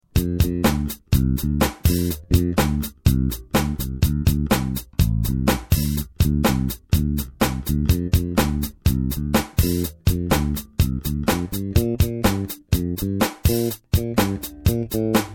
Der Bass klingt sauber, aufger�umt, mit einer hervorragenden H-Saite. Und damit meine ich nicht, dass sie donnert, sondern dass sie sich v�llig ausgeglichen zu E- und A-Saite gesellt und genauso obertonreich ausklingt.